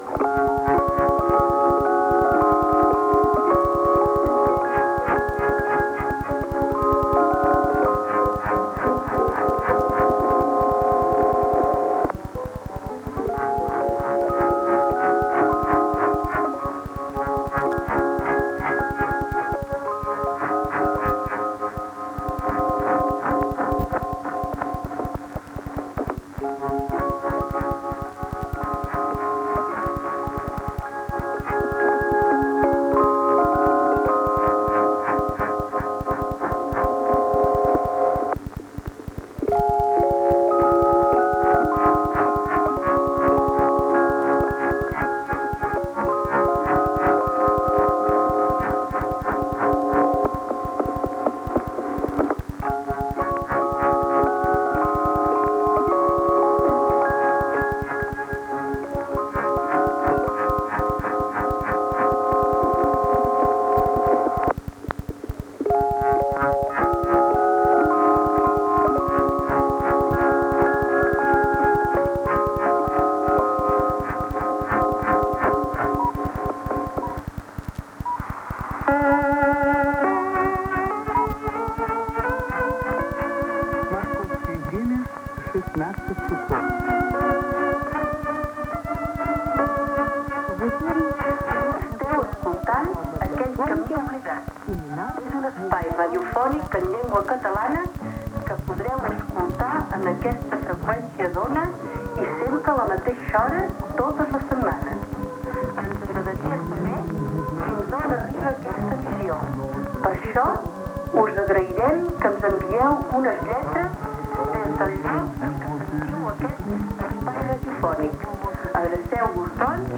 Sintonia i inici de l'emissiò en català i del programa evangèlic.
Recepció amb alguna interferència de Ràdio Moscou.